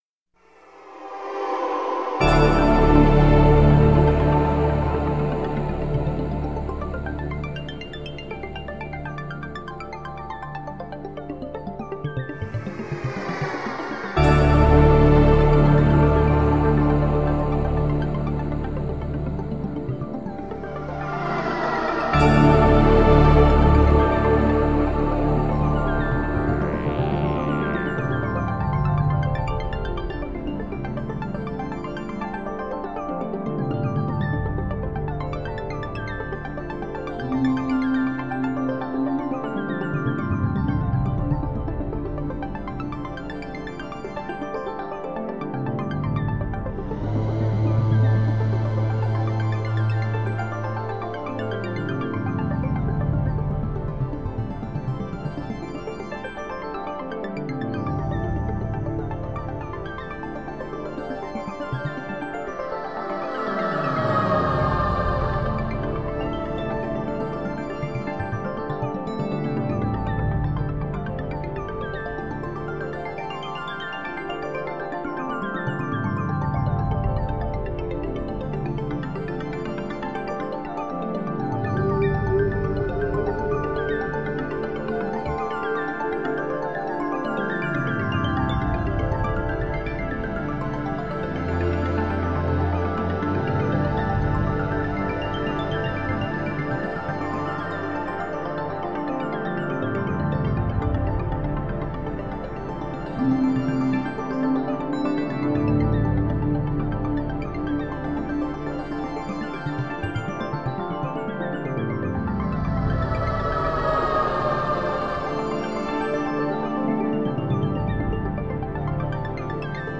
Beautiful ambient space music.
Tagged as: Ambient, New Age, Space Music